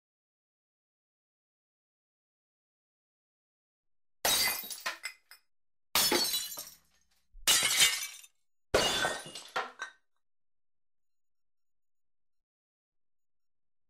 دانلود صدای شکستن لیوان و شیشه 1 از ساعد نیوز با لینک مستقیم و کیفیت بالا
جلوه های صوتی